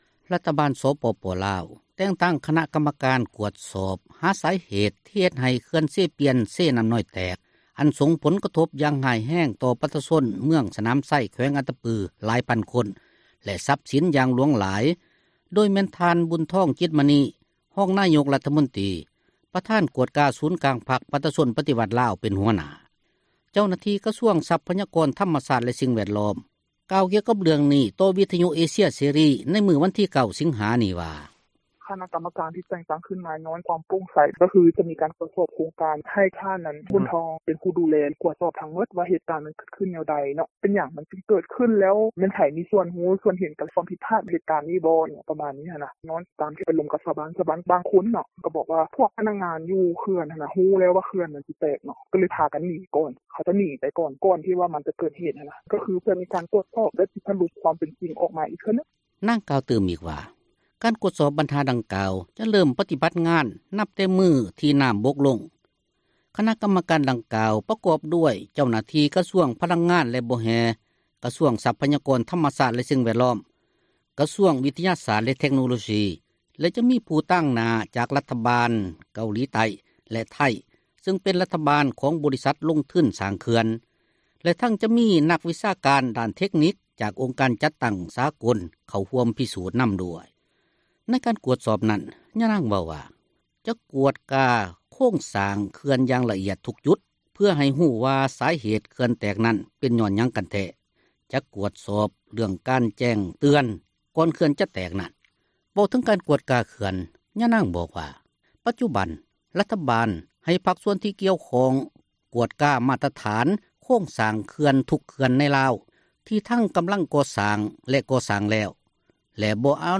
ເຈົ້າໜ້າທີ່ກະຊວງຊັພຍາກອນທັມຊາດ ແລະສິ່ງແວດລ້ອມ ກ່າວກ່ຽວກັບເຣຶ່ອງນີ້ຕໍ່ວິທຍຸເອເຊັຍເສຣີ ໃນມື້ວັນທີ 9 ສິງຫານີ້່ວ່າ: